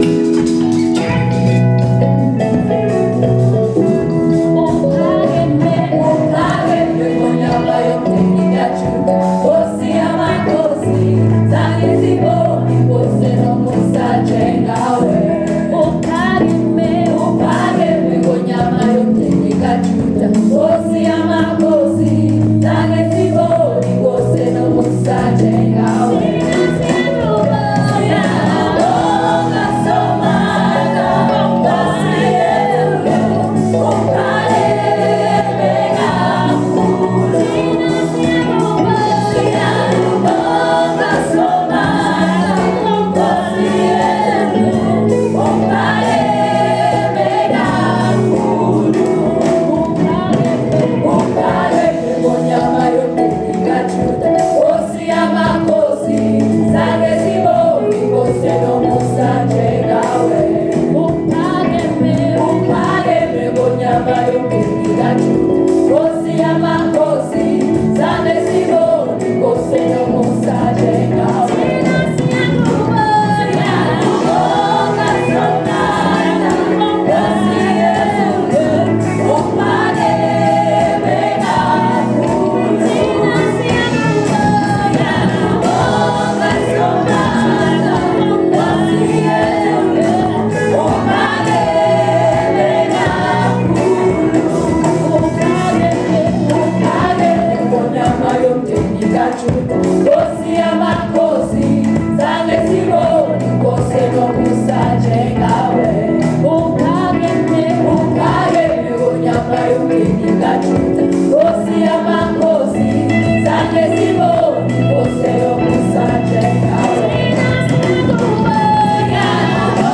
Love joining the LSM Inner City crowd for church on the occasional Sunday morning. Nothing like trying to get my tongue around all the different languages when we sing :) This song is in isiZulu. Uphakeme means "You are lifted up".